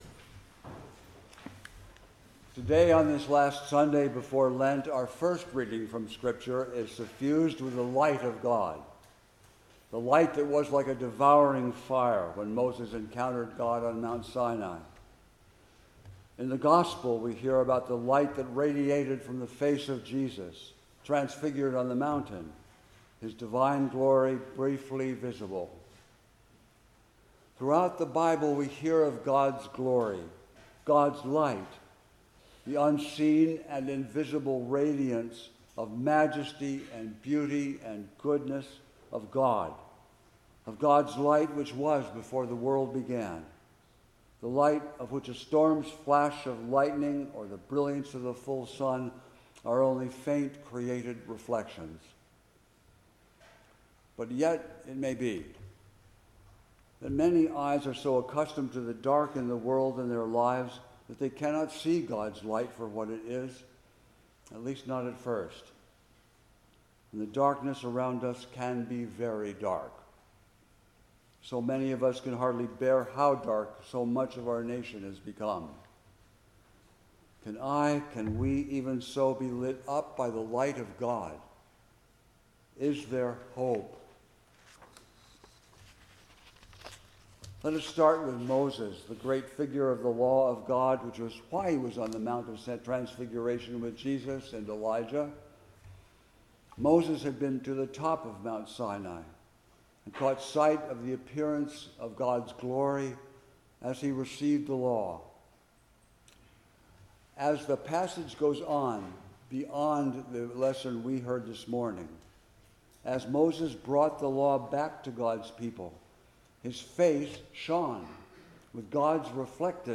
Passage: Exodus 24:12-18, Psalm 99, 2 Peter 1:16-21, Matthew 17:1-9 Service Type: 10:00 am Service